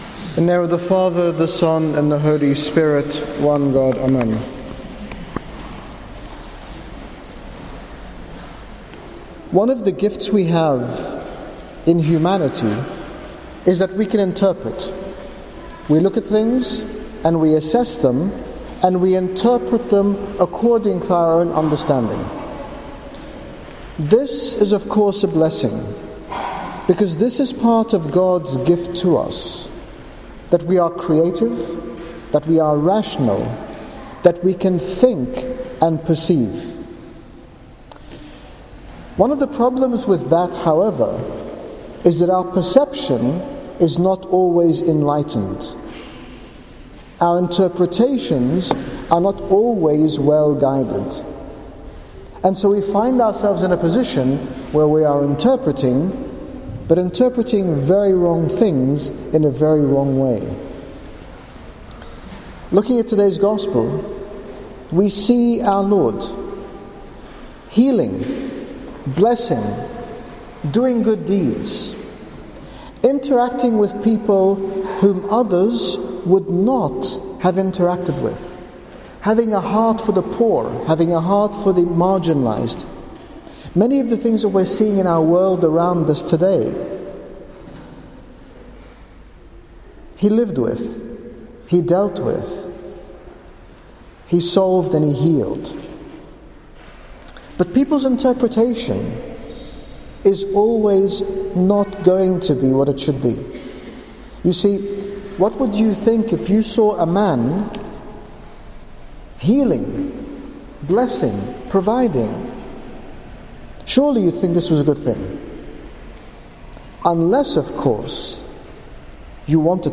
In this short sermon at St Paul Ministry, His Grace Bishop Angaelos urges us to see others and to love all without prejudice, following in the footsteps and the example of our Lord Jesus Christ.